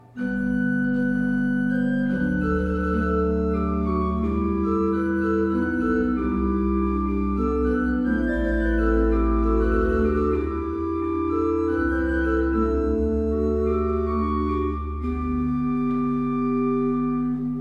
kostel sv. Jana Nepomuckého
Nahrávky varhan:
Vsemina, Kopula major, Kvinta 3, Rohrflauta 8.mp3